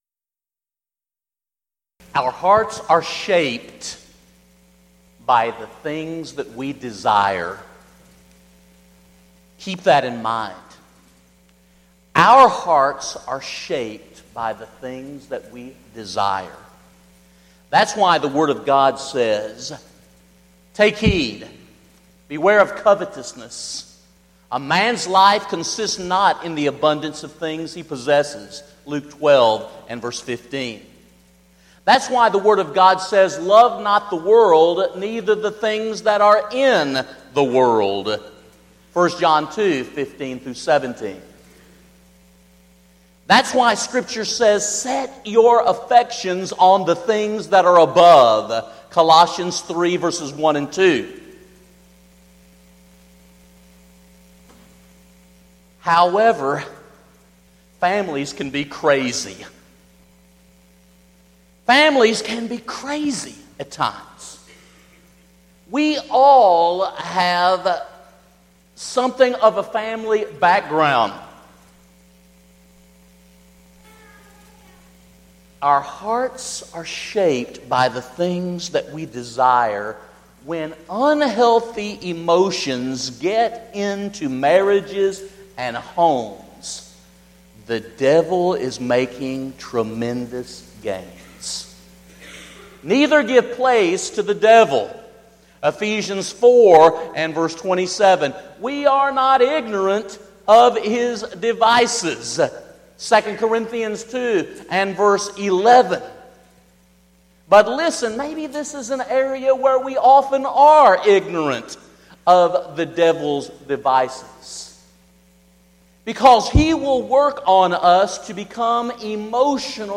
Event: 3rd Annual Southwest Spritual Growth Workshop
lecture